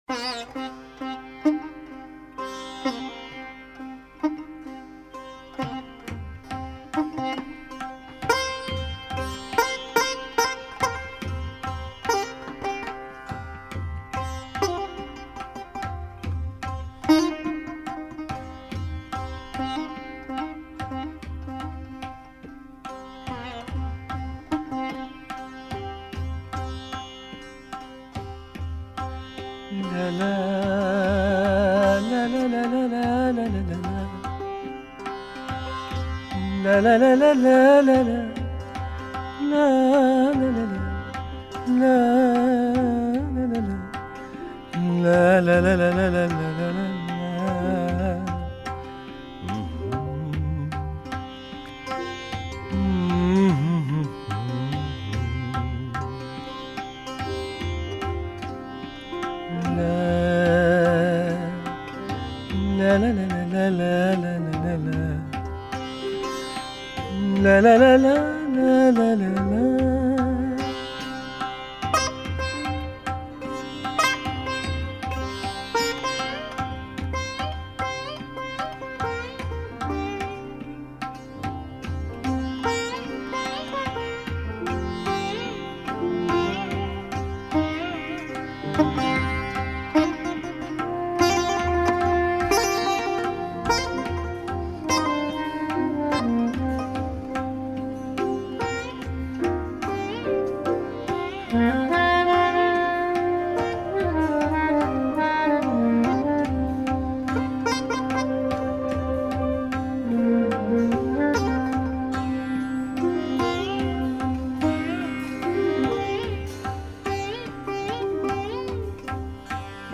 spiritual / Indian jazz session